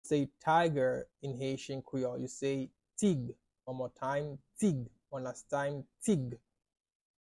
“Tiger” in Haitian Creole – “Tig” pronunciation by a native Haitian teacher
“Tig” Pronunciation in Haitian Creole by a native Haitian can be heard in the audio here or in the video below:
How-to-say-Tiger-in-Haitian-Creole-–-Tig-pronunciation-by-a-native-Haitian-teacher.mp3